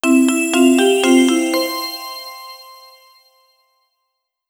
fireworks.wav